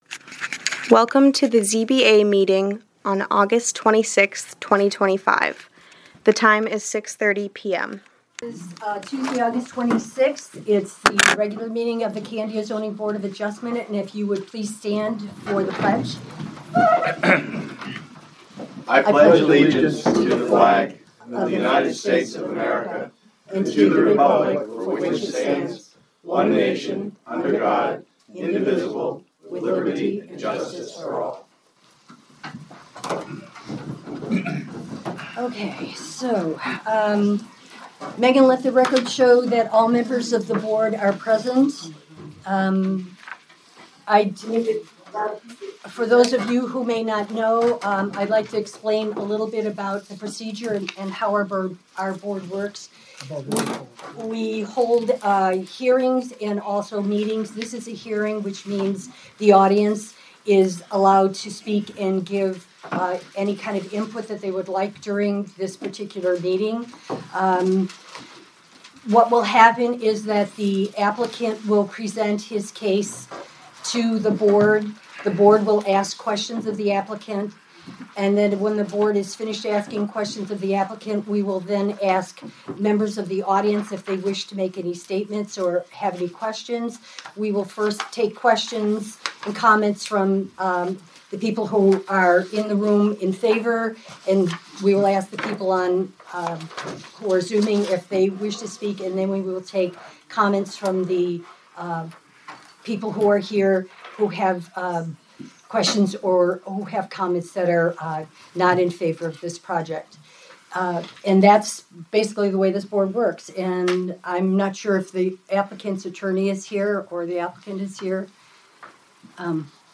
Audio recordings of committee and board meetings.
Zoning Board of Adjustment Meeting